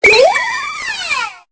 Cri de Mélancolux dans Pokémon Épée et Bouclier.